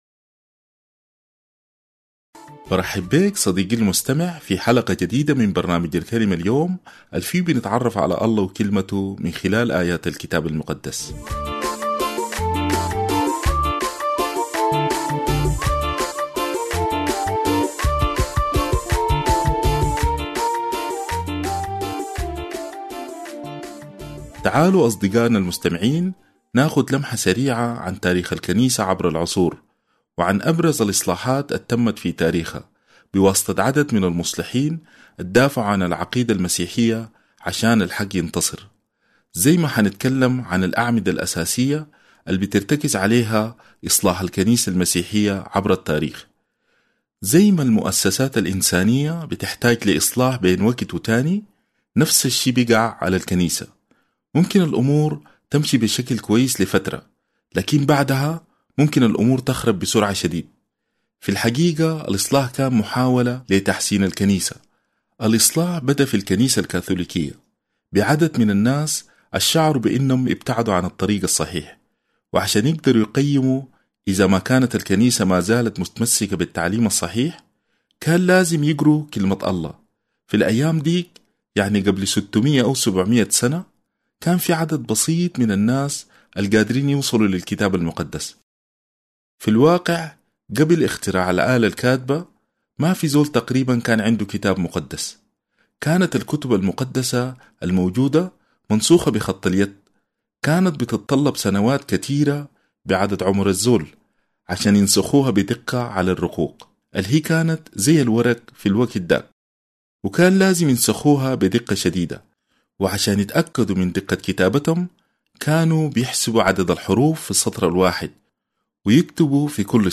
الكلمة اليوم باللهجة السودانية